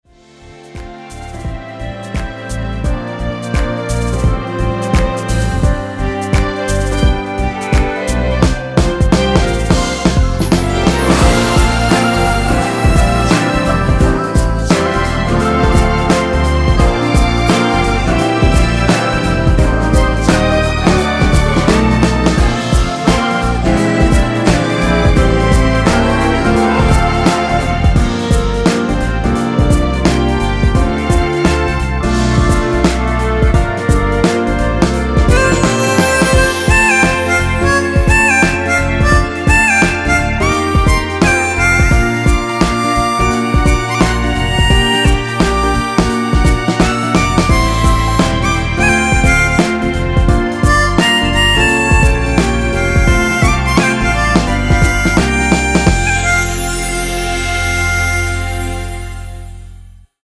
엔딩이 페이드 아웃이라서 노래하기 편하게 엔딩을 만들어 놓았으니 미리듣기 확인하여주세요!
원키에서(-3)내린 멜로디와 코러스 포함된 MR입니다.
앞부분30초, 뒷부분30초씩 편집해서 올려 드리고 있습니다.